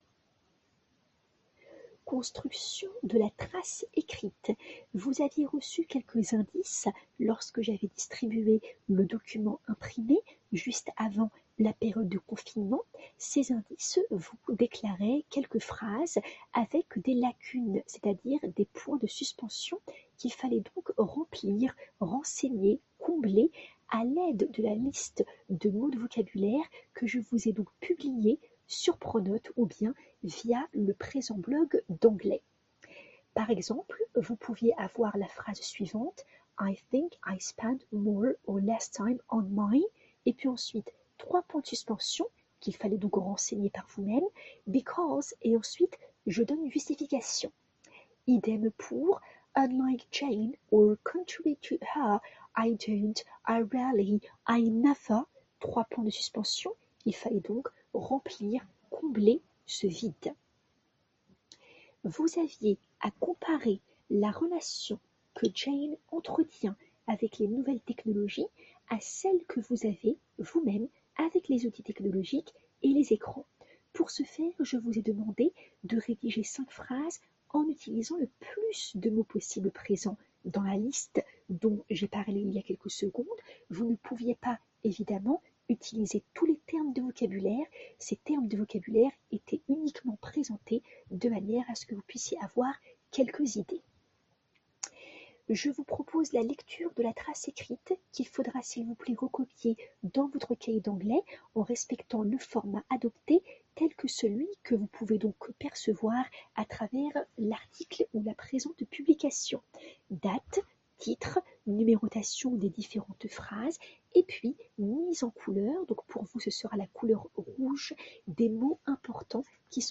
Veuillez trouver ci-après, s'il vous plaît, et sous forme d'enregistrements vocaux, le corrigé des activités faisant partie de la construction de la leçon précédente:
Audio 2 du professeur d'une durée de 03:05: